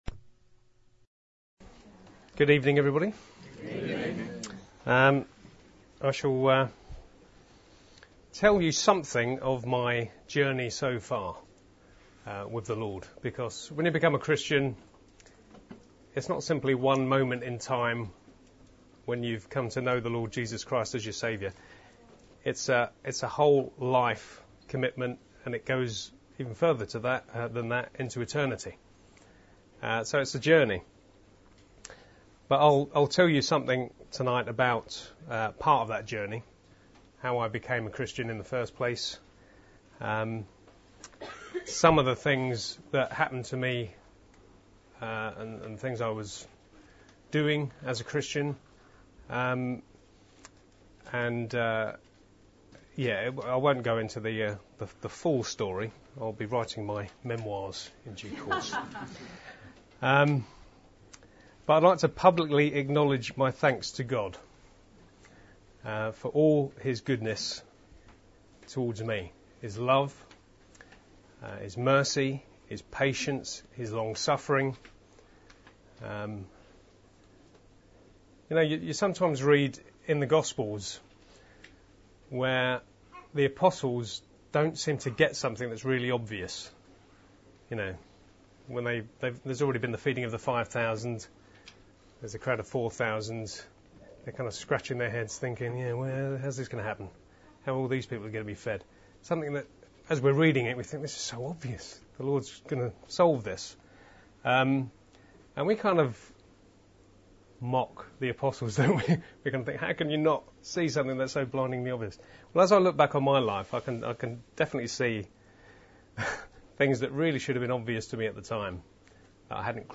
July 23, 2013; Testimony